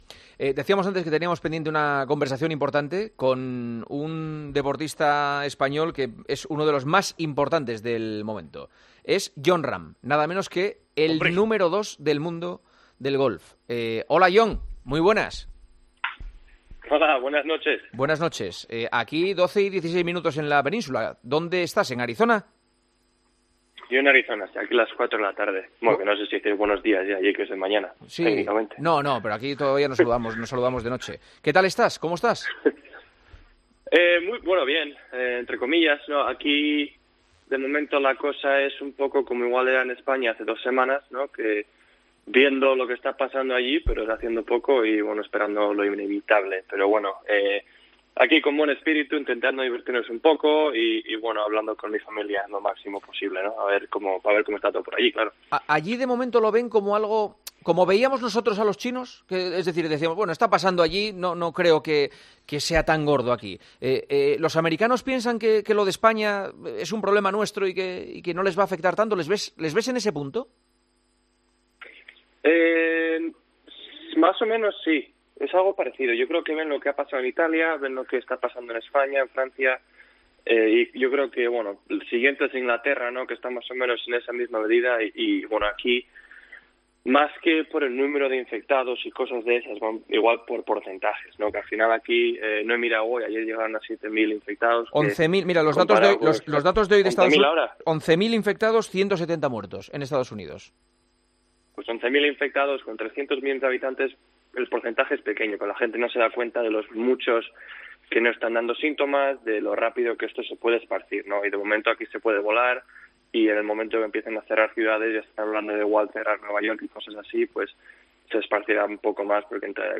Hablamos con el golfista español, uno de los mejores del mundo, sobre la crisis del coronavirus.